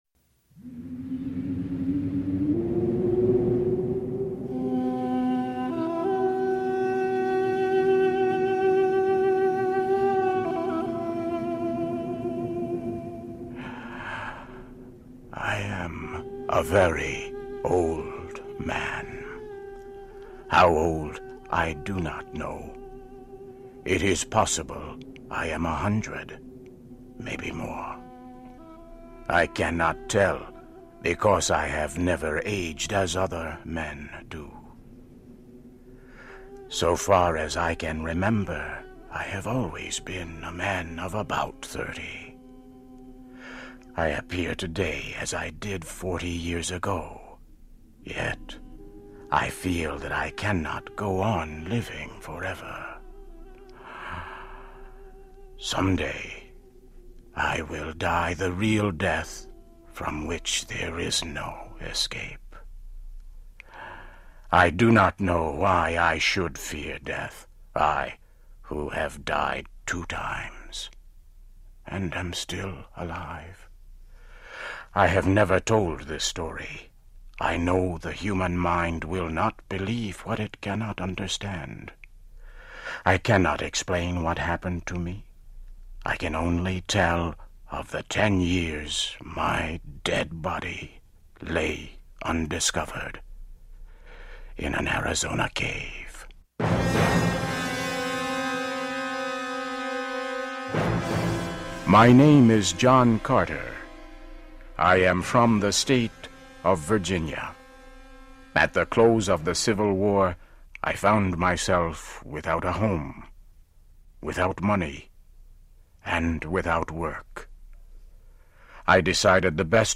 'A Princess of Mars,' by Edgar Rice Burroughs, Part 1 - BOOK.mp3